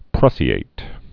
(prŭsē-āt)